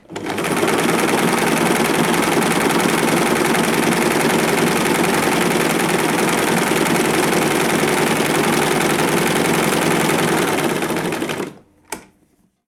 Máquina de coser 1
máquina de coser
Sonidos: Industria
Sonidos: Hogar